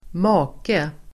Uttal: [²m'a:ke]